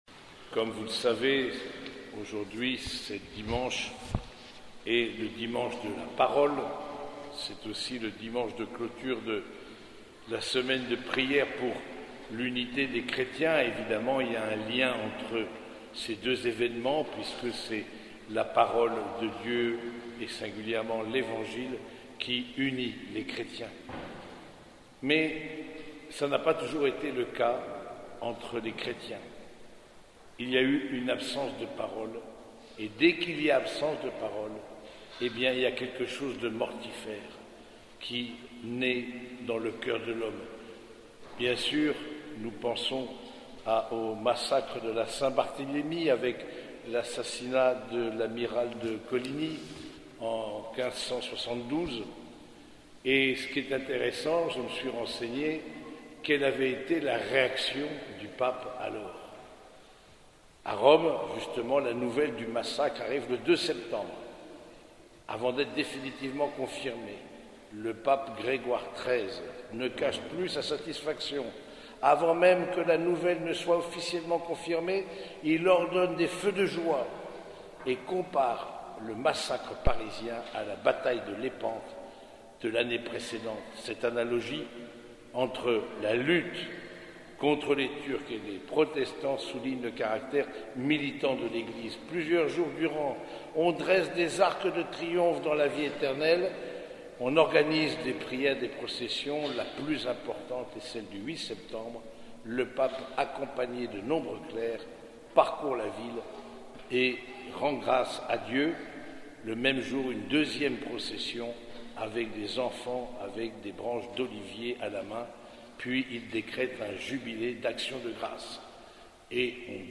Homélie du troisième dimanche de Pâques